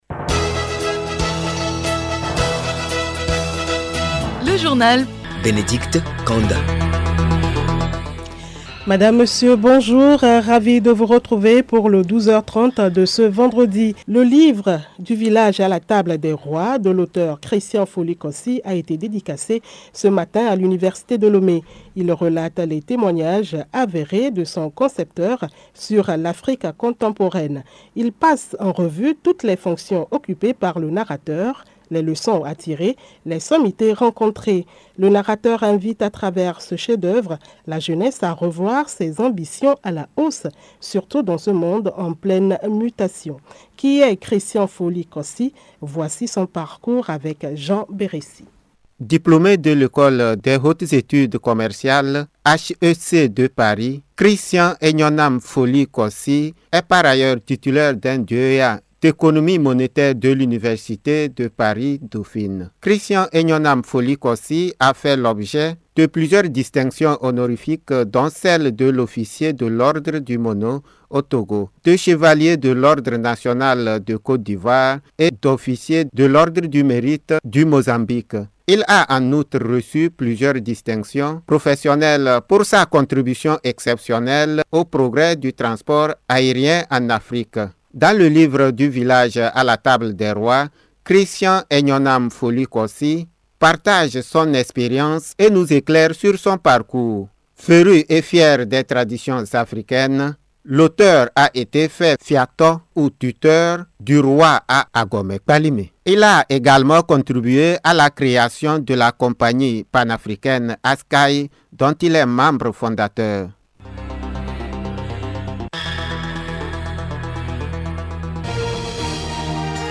Émission Radio